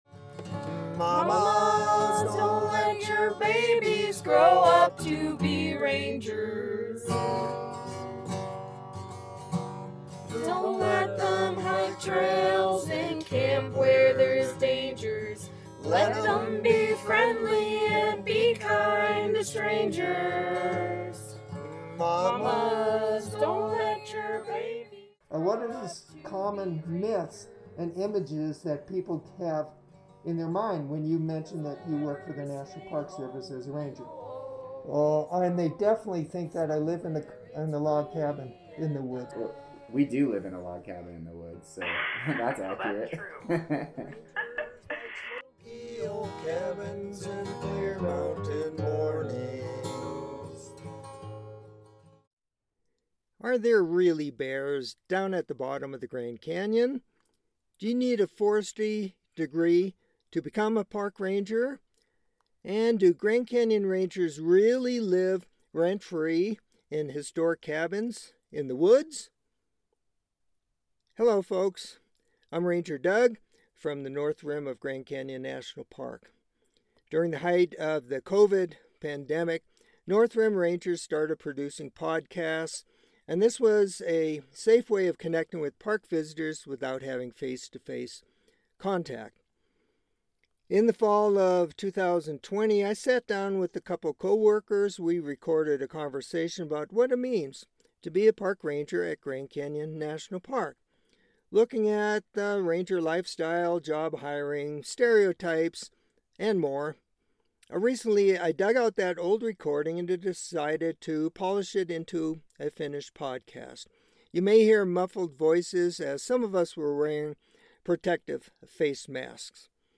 Disregarding the tongue-in-cheek podcast title, three Grand Canyon Rangers sit down for a fun roundtable discussion about park ranger work, lifestyles, and stereotypes. There are many perspectives on park ranger life, this episode explores the experience of three individuals.